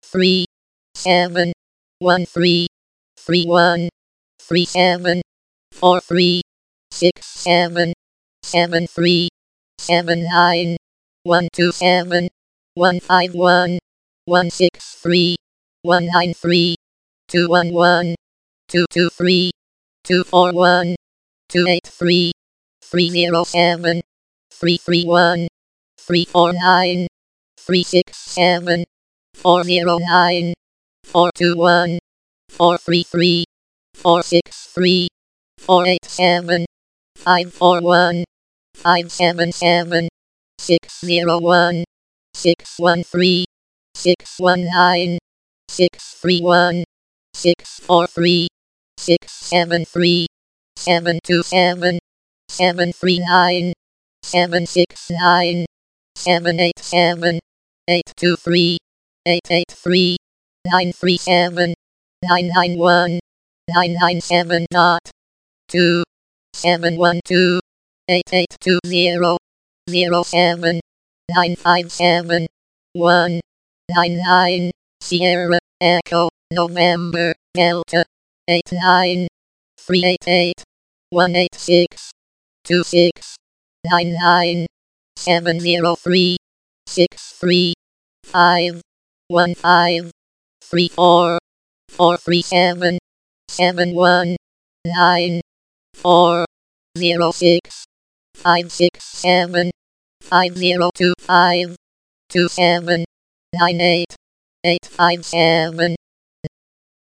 a robot voice reading out a series of numbers and occasionally words from the NATO phonetic alphabet, see status text for content